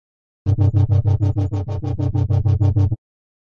描述：使用tunefish4在vsthost中制作，注意是C，120 bpm
Tag: WUB-WUB WUB低音 调整warb低音 warbwarb 虚拟模拟 主机warb乐 低音 tunefish 合成器 4 合成器 warb-warb 合成的 tunefish4 类似物 电子 颤声低音 wubwub VST vsthost